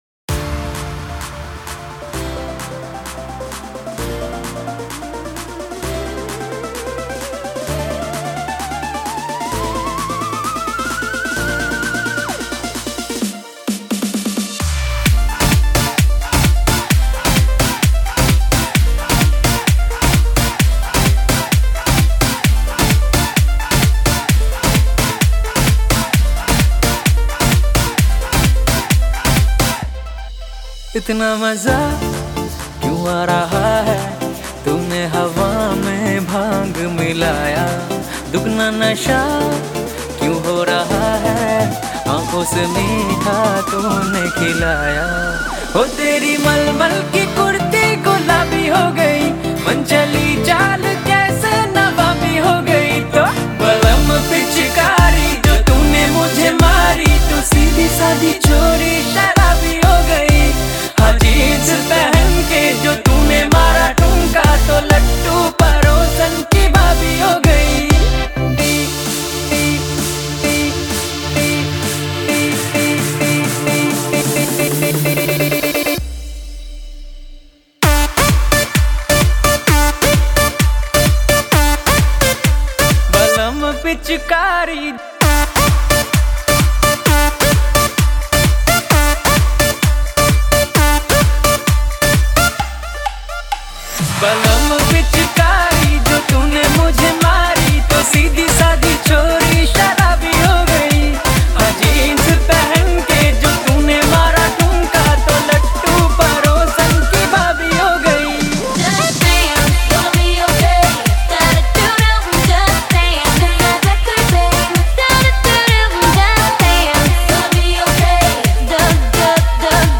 HOLI SPECIAL DJ SONG Songs Download